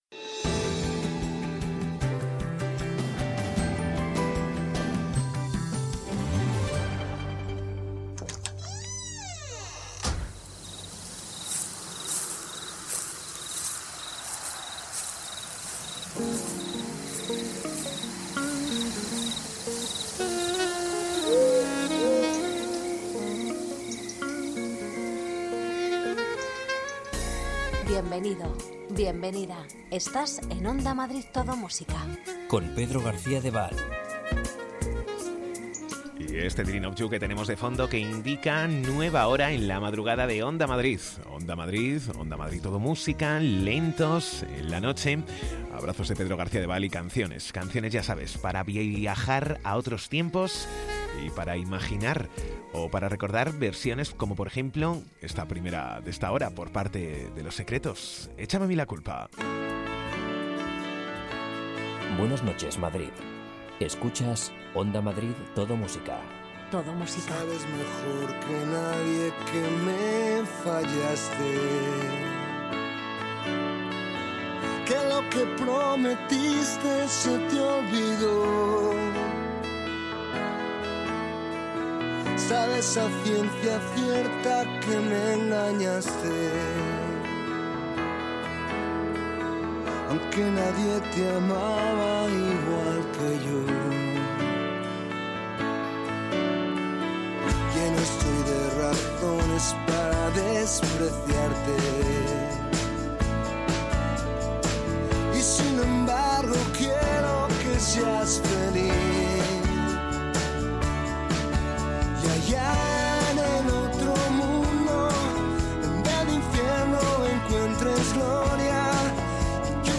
Ritmo tranquilo, sosegado, sin prisas.